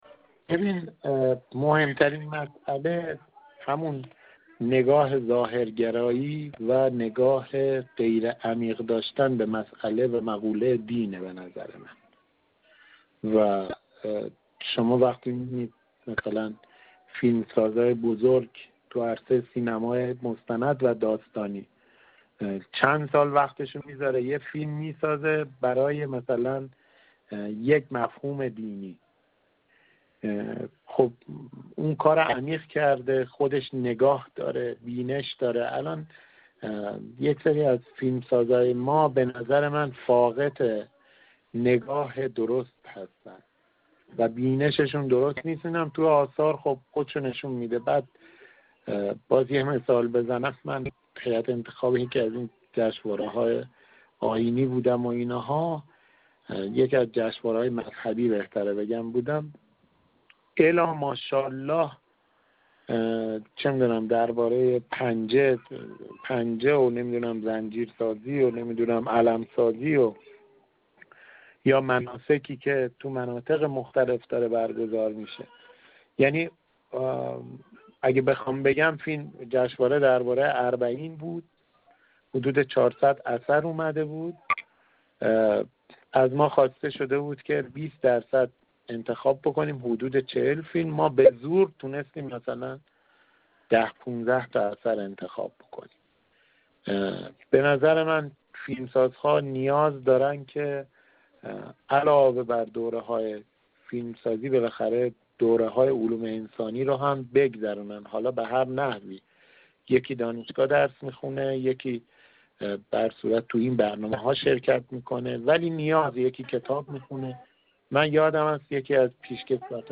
در گفت‌و‌گو با ایکنا: